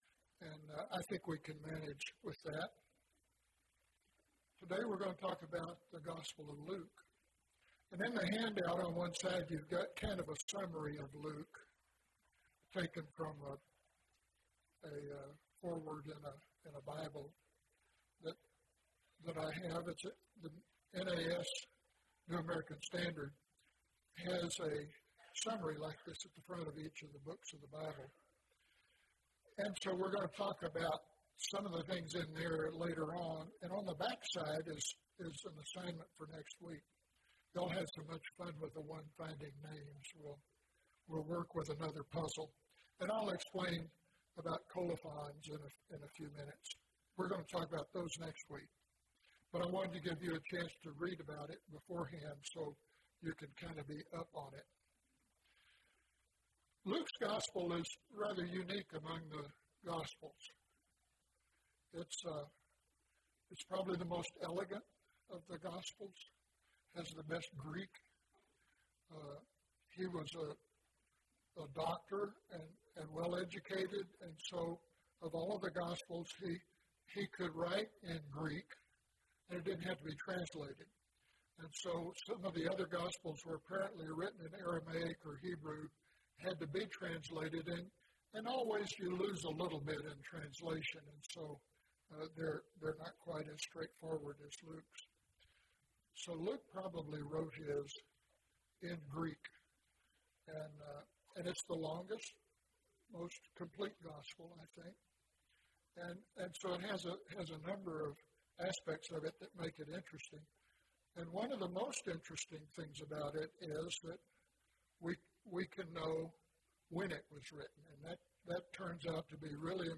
The Writing of Luke’s Gospel (9 of 14) – Bible Lesson Recording